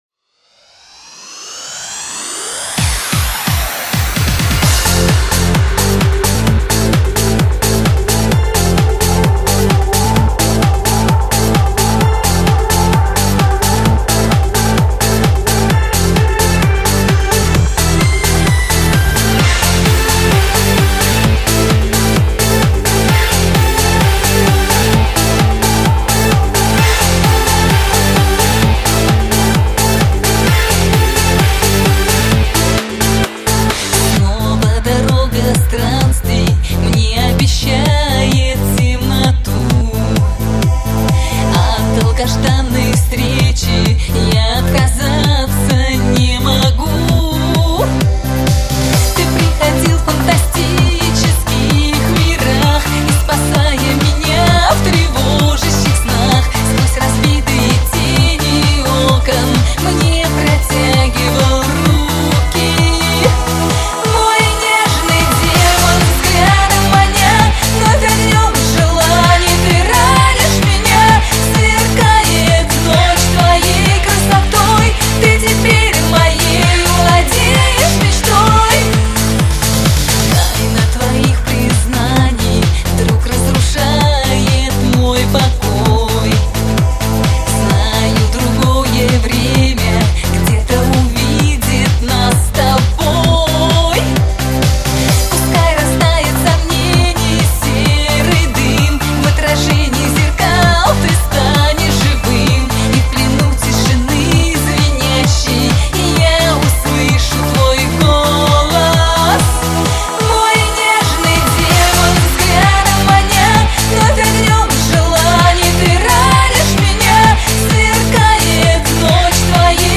Жанр: Эстрада, Попса